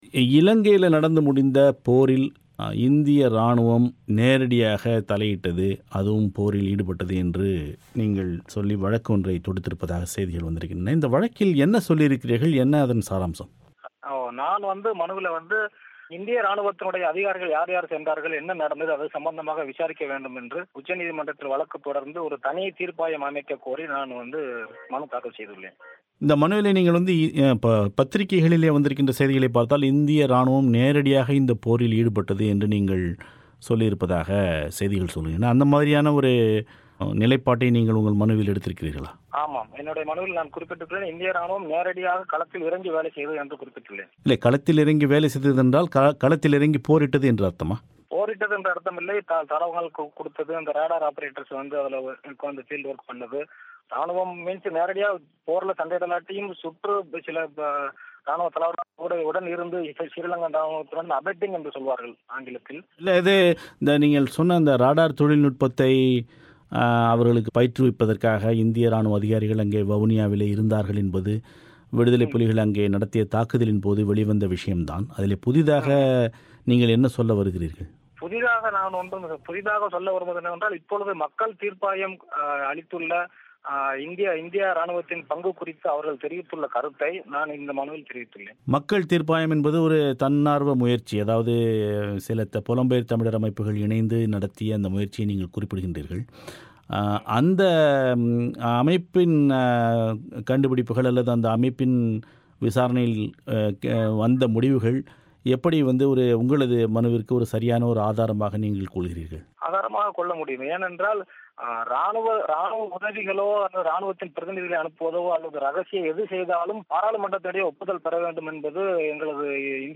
அவரது செவ்வியை இங்கு கேட்கலாம்.